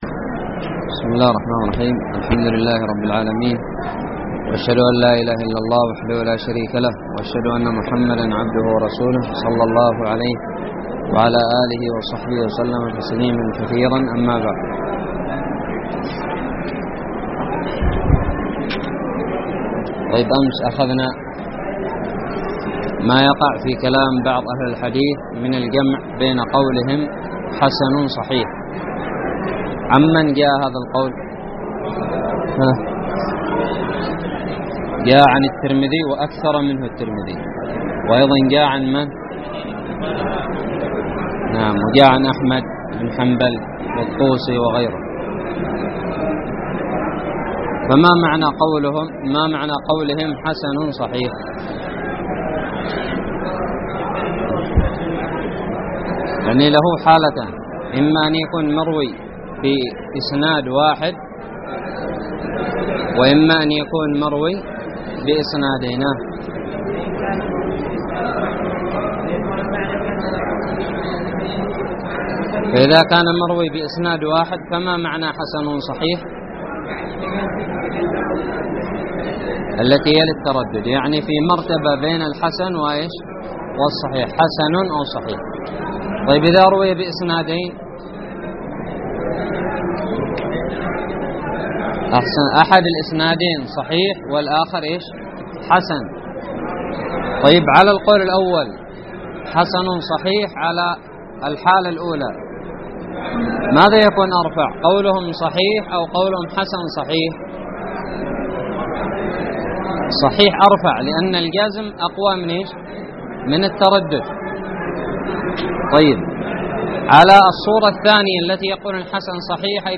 الدرس الرابع عشر من شرح كتاب نزهة النظر
ألقيت بدار الحديث السلفية للعلوم الشرعية بالضالع